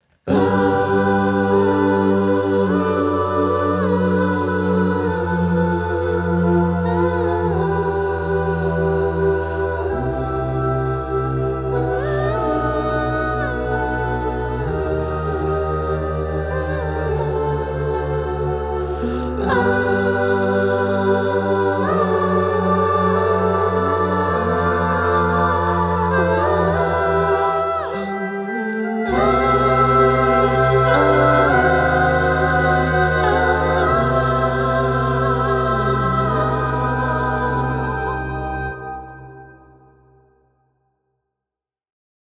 It's an emo album about being a sad bunny.
Guitar, bass, bg vocals